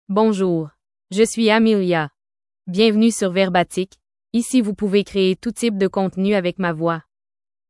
FemaleFrench (Canada)
AmeliaFemale French AI voice
Amelia is a female AI voice for French (Canada).
Voice sample
Amelia delivers clear pronunciation with authentic Canada French intonation, making your content sound professionally produced.